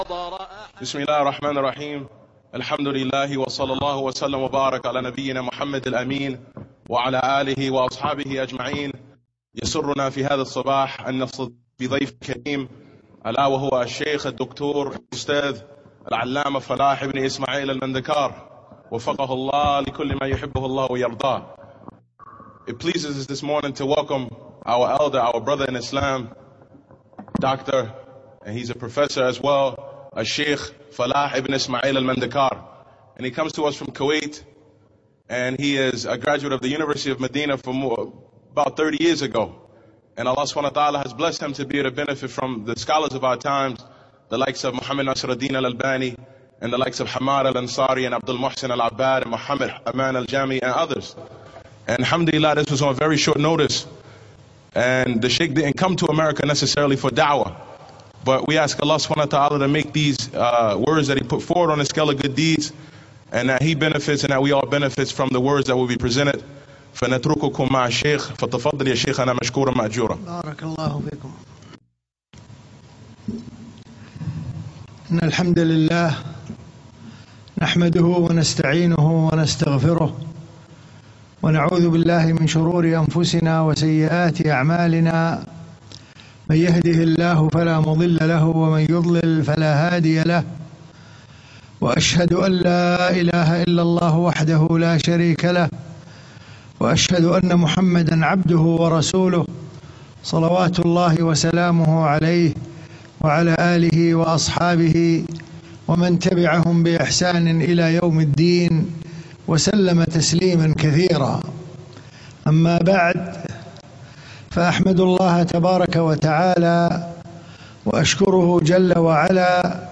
اللقاء في أحد سجون أمريكا